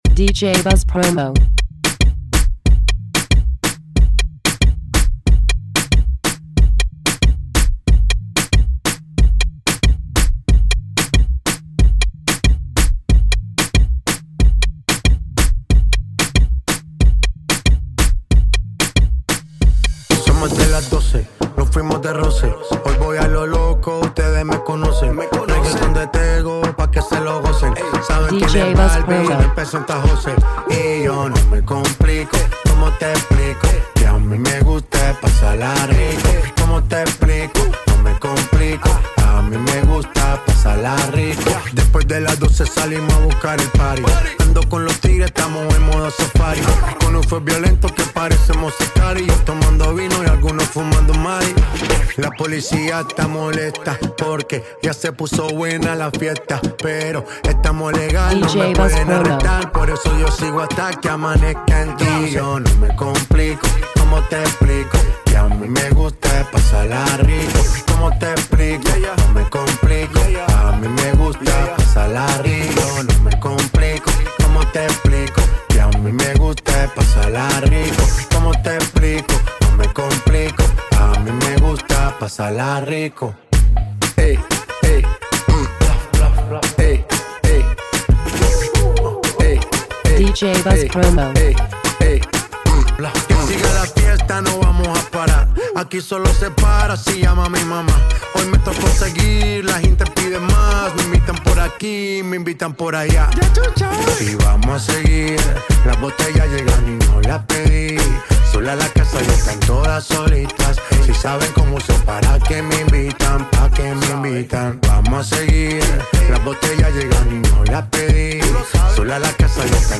reggaeton